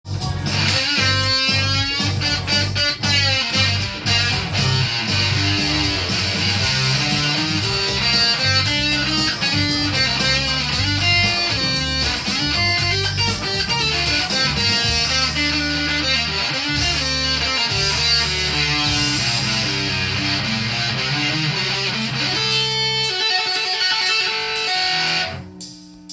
ギターソロを弾くとどうなるでしょうか？
この感じは。。。サンタナに似た音になっています。
ソロを弾くとサンタナ風に聞こえるので不思議なものです。
bigmaff.solo_.m4a